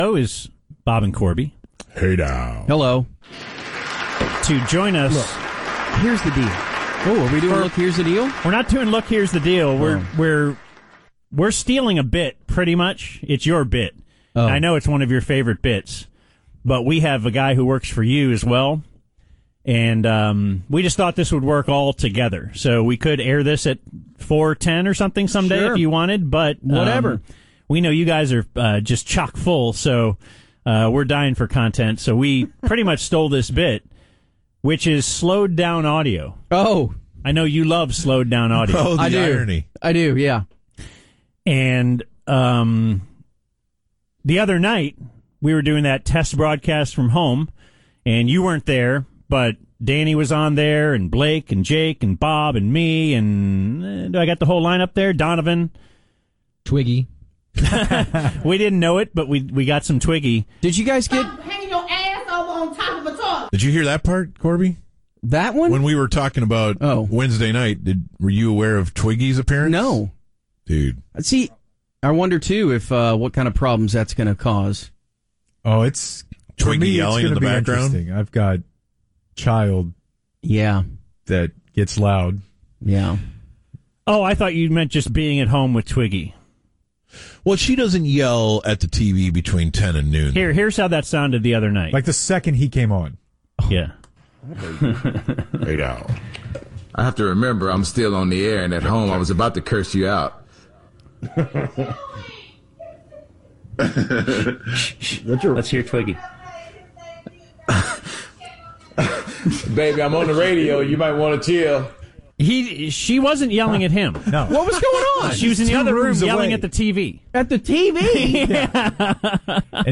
BaD Radio – Slowed Down